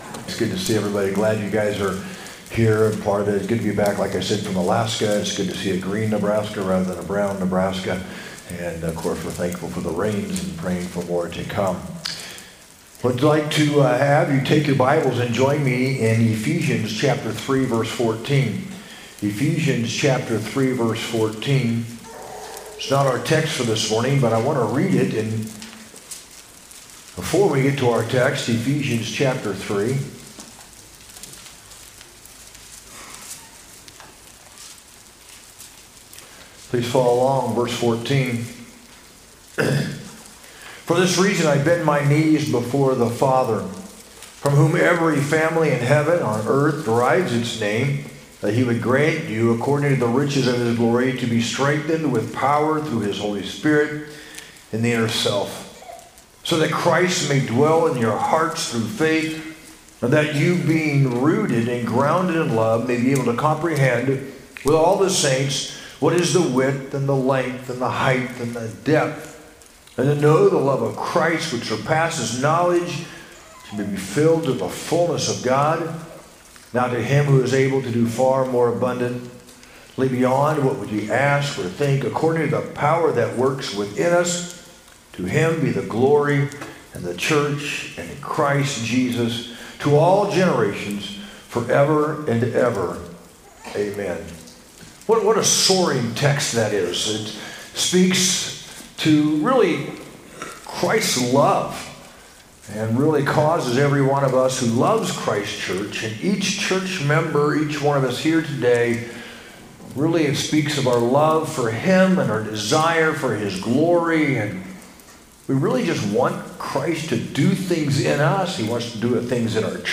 sermon-6-1-25.mp3